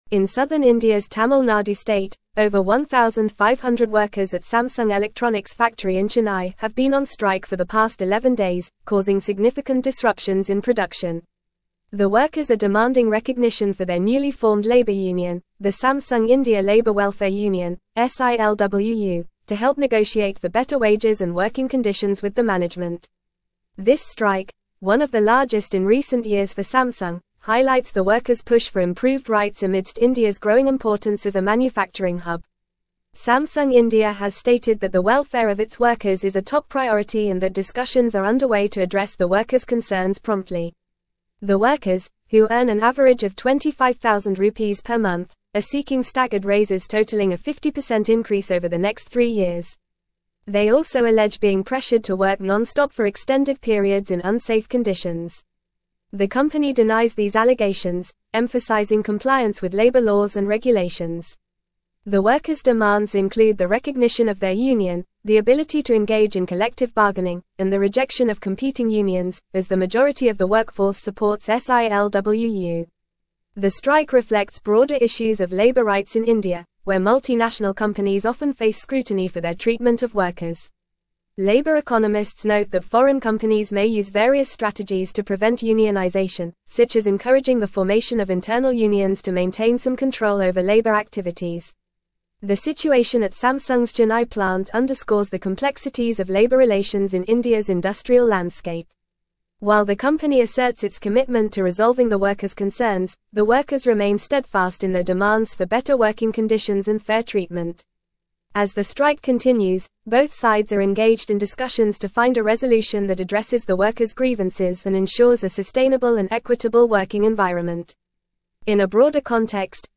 Fast
English-Newsroom-Advanced-FAST-Reading-Samsung-Workers-in-India-Strike-for-Union-Recognition.mp3